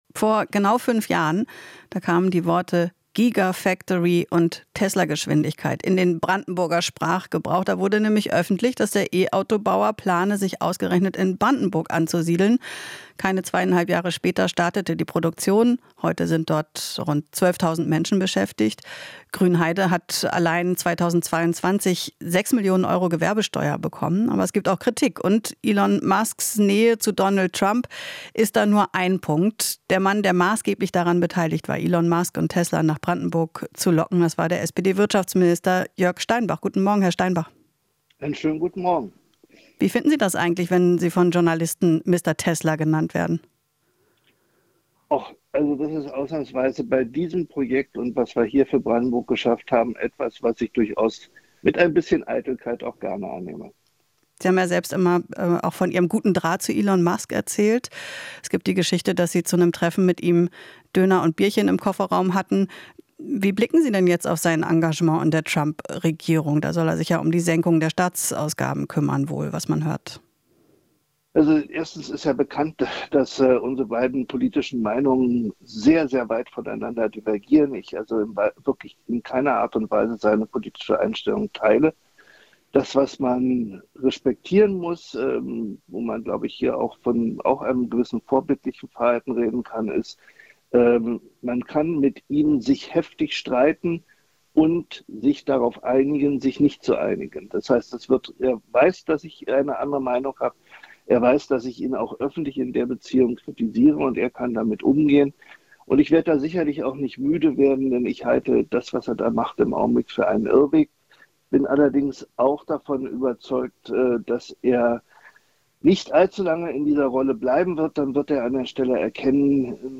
Interview - Steinbach (SPD): Elon Musk ist auf einem Irrweg